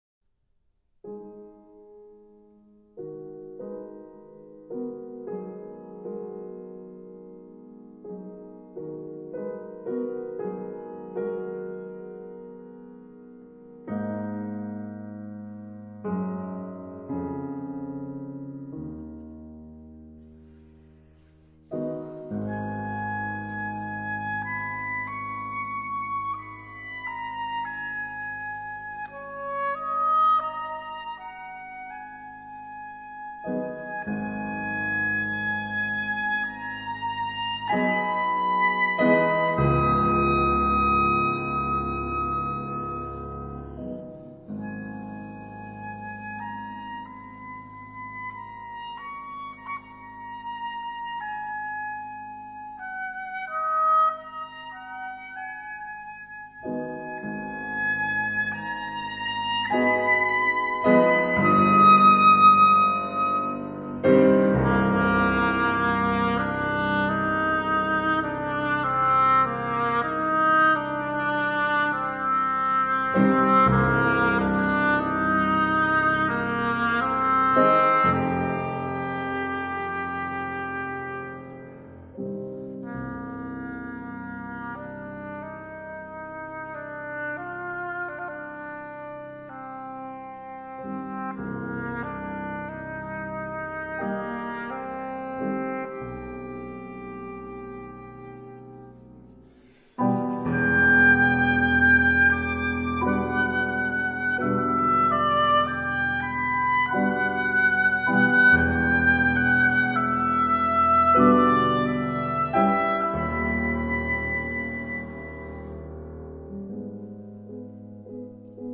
晚安曲分享的是張雙簧管與鋼琴，
心裡一跳，這Oboe好動人，質感又美。
這兩張是在同一段時間，於於德國斯圖加特錄音的。